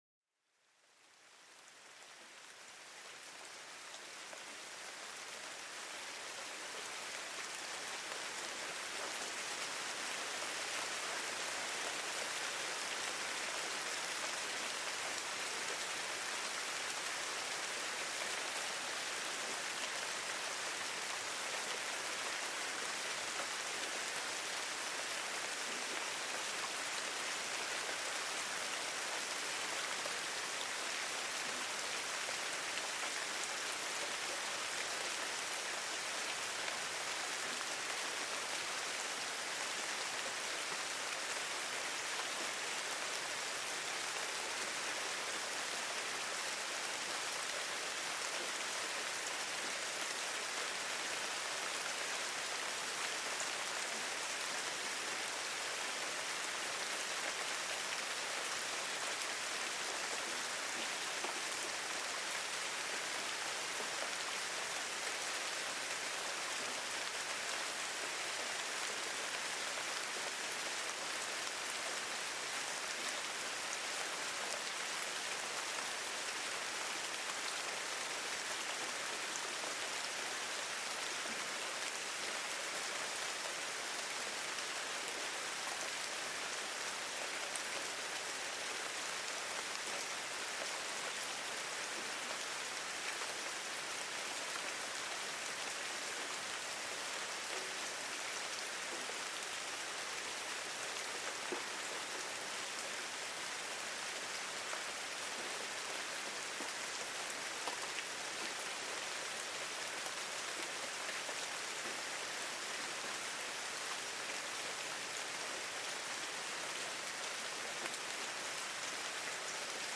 Pluie battante pour une détente en profondeur